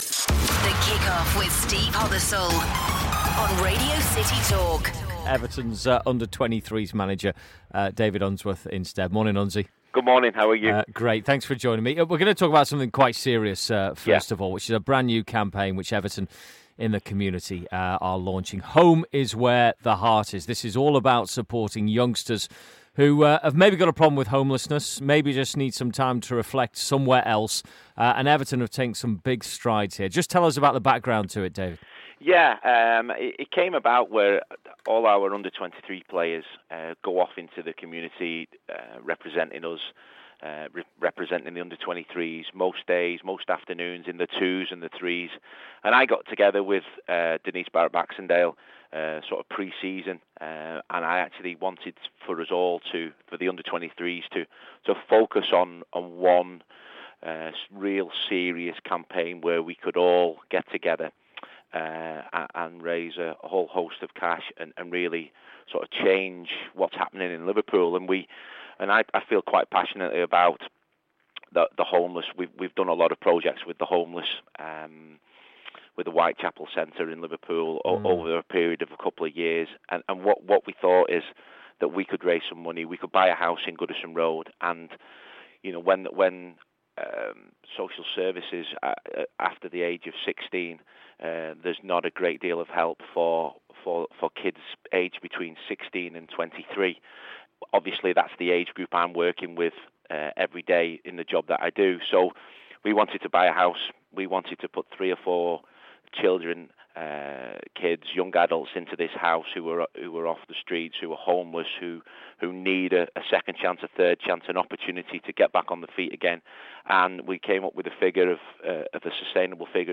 Everton's under-23 manager speaks to us about the work they're doing to help the homeless in the city